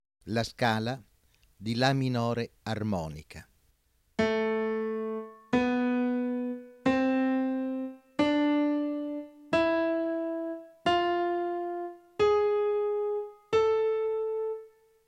07. Ascolto della scala di La minore armonica.
07_La_m_armonica.wma